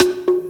Bongos Two.wav